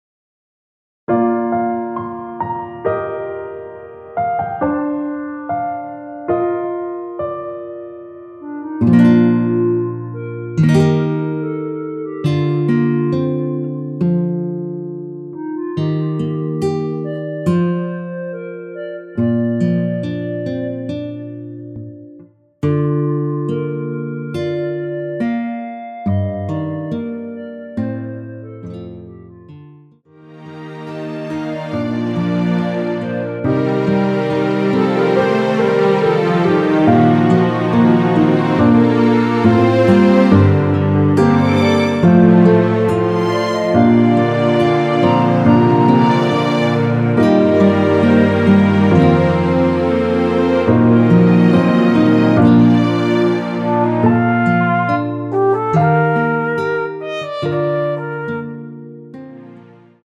원키에서(+1)올린 멜로디 포함된 MR입니다.
Bb
앞부분30초, 뒷부분30초씩 편집해서 올려 드리고 있습니다.
중간에 음이 끈어지고 다시 나오는 이유는